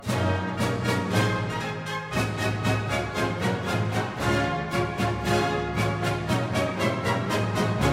In the middle, though, the flutes and piccolos bring in a major key section that sounds happy and victorious. This is to symbolize the Russians coming to the Serbians' aid: